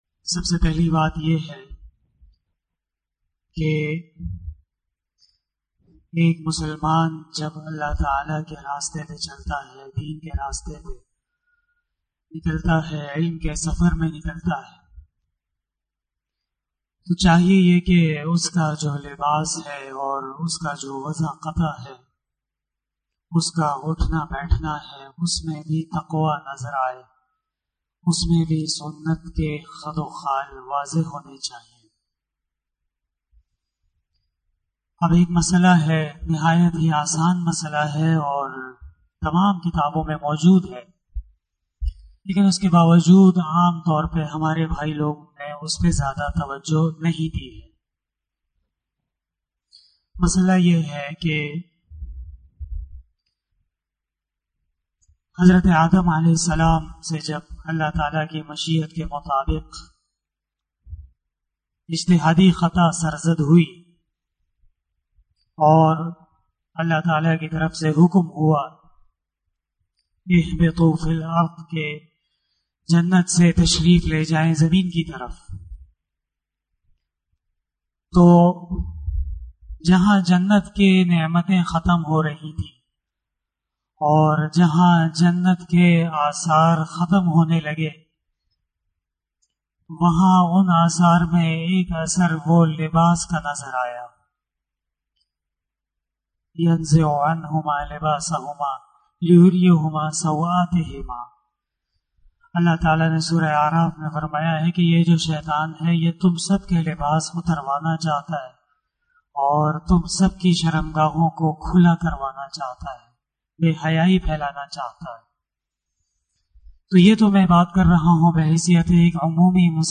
024 After magrib Namaz Bayan 02 June 2021 ( 21 Shawwal 1442HJ) Wednesday
بیان بعد نماز مغرب